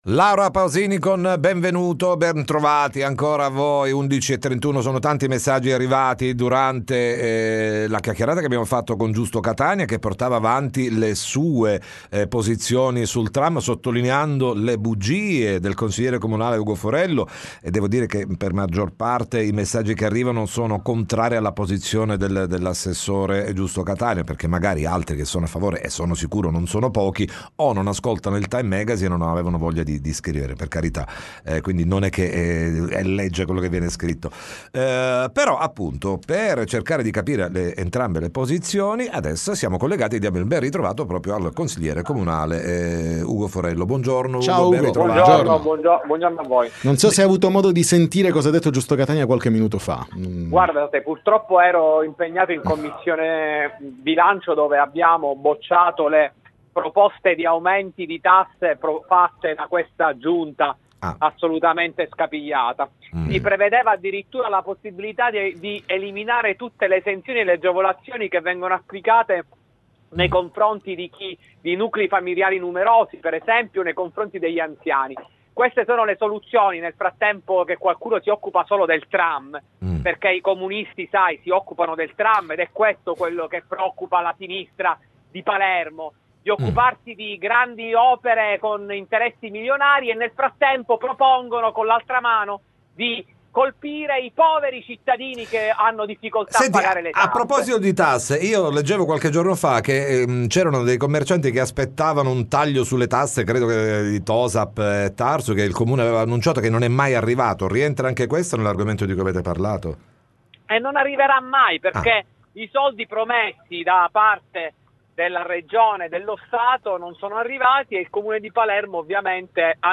TM Intervista Ugo Forello